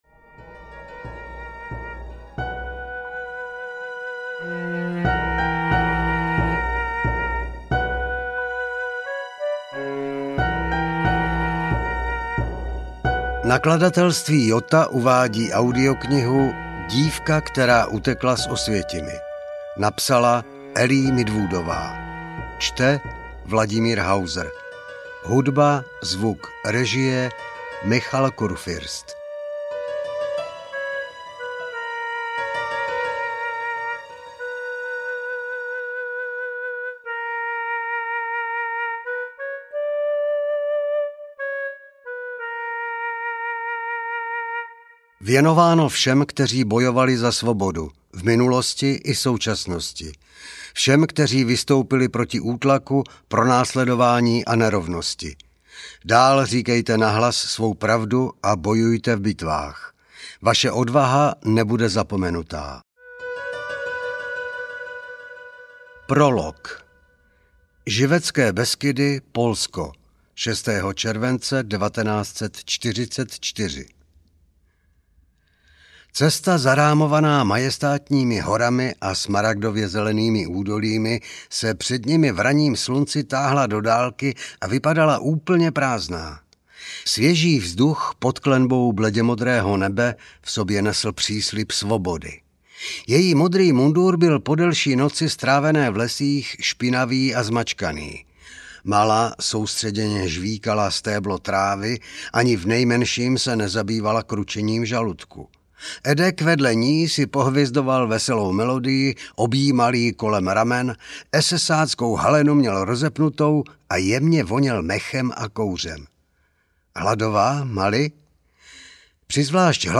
Audiokniha Dívka, která utekla z Osvětimi, kterou napsala Ellie Midwoodová. Román na motivy skutečného příběhu Maly Zimetbaumové, která jako první žena utekla z Osvětimi.
Ukázka z knihy